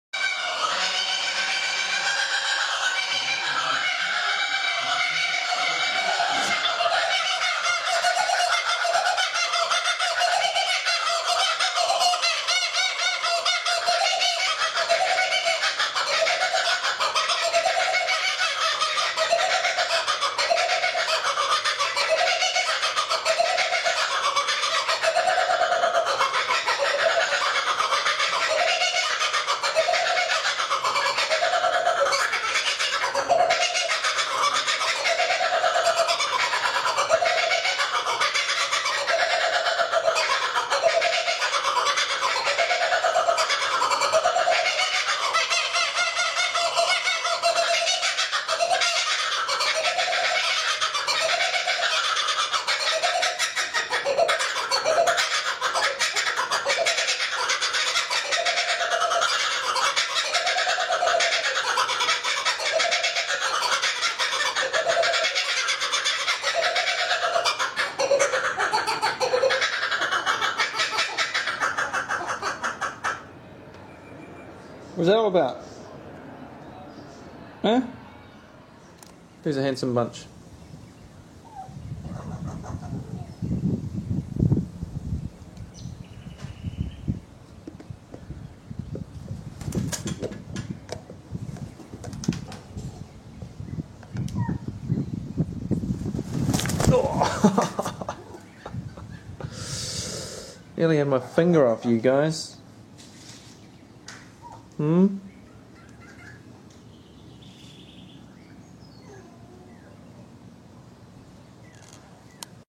Kookaburra - Zoo de Bordeaux Pessac
Pour écouter le chant du kookabura
Kookaburra-call-1.mp3